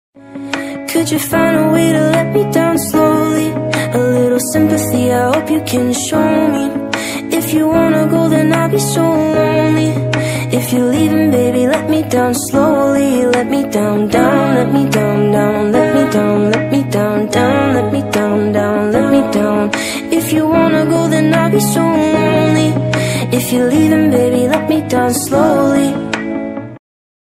Sad Ringtone।